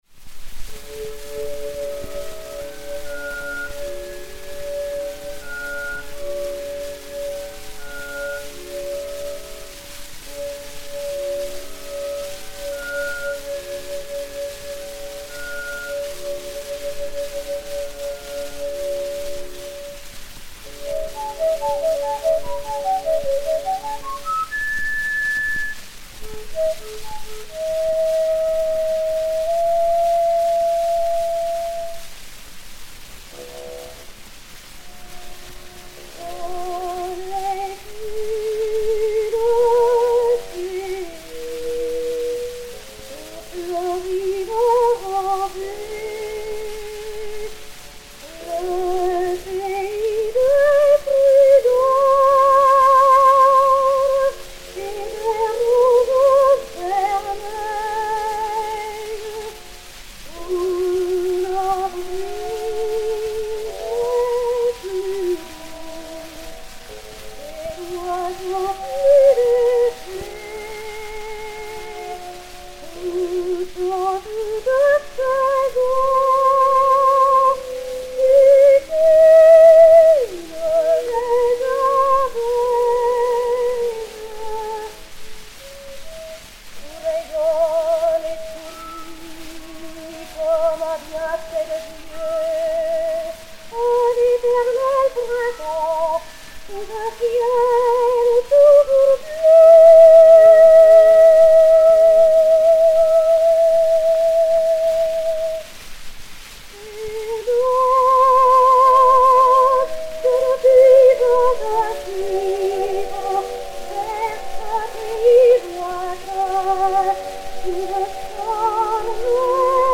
enr. à Paris en 1906